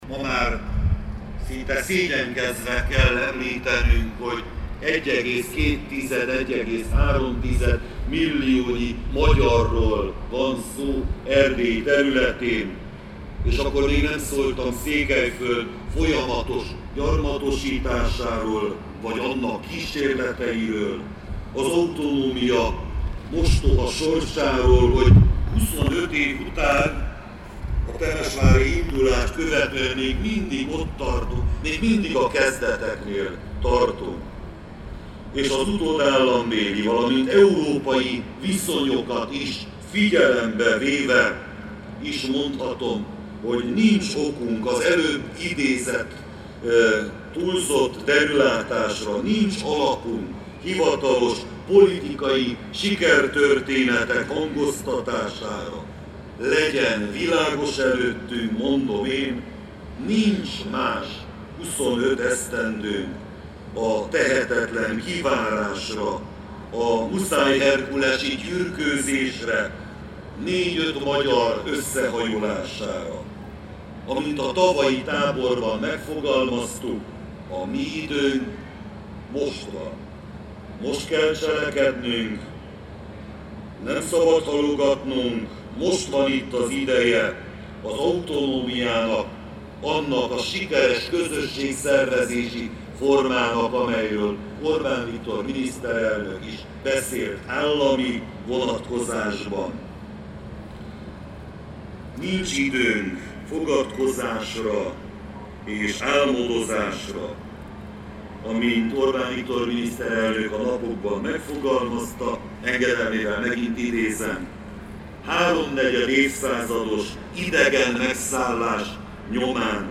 Tusvanyos_Tokes_Laszlo_beszede.mp3